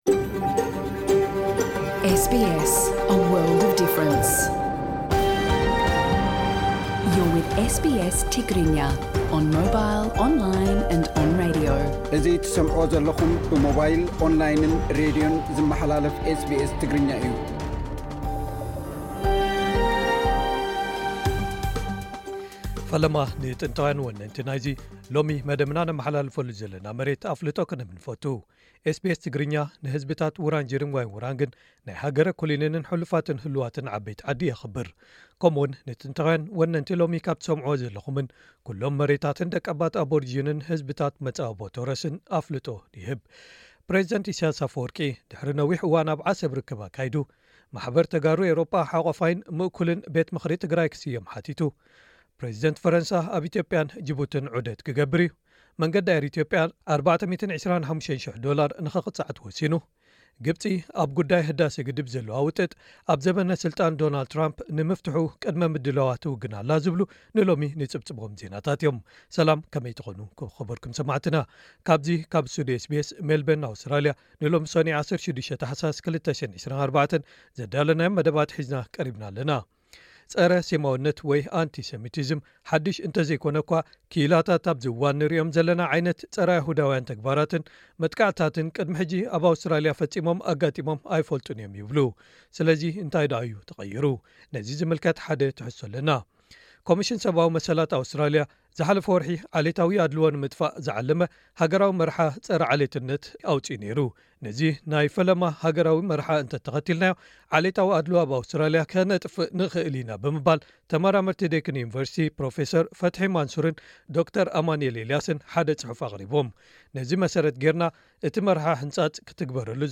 ቀጥታ ምሉእ ትሕዝቶ ኤስ ቢ ኤስ ትግርኛ ፖድካስት (12 ታሕሳስ 2024)
ጸብጻብ ዜናታት - ዞባዊን ዓለማውን